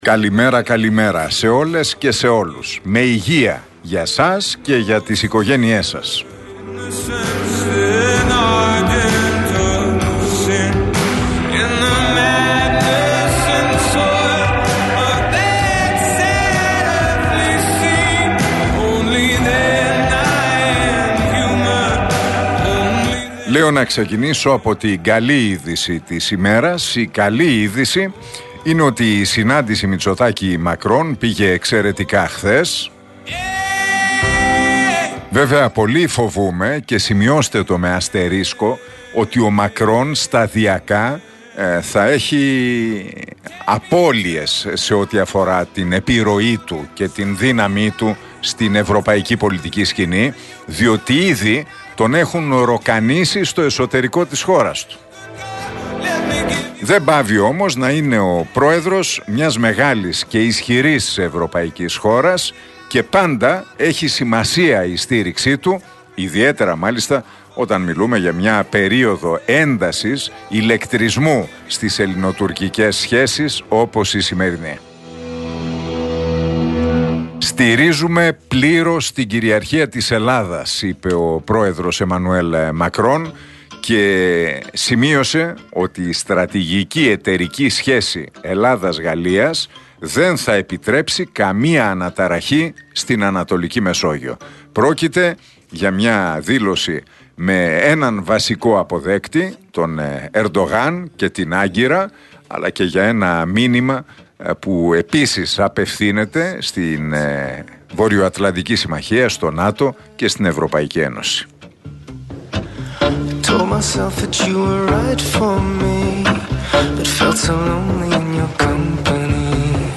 Ακούστε το σημερινό σχόλιο του Νίκου Χατζηνικολάου (13 Σεπτεμβρίου 2022).